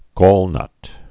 (gôlnŭt)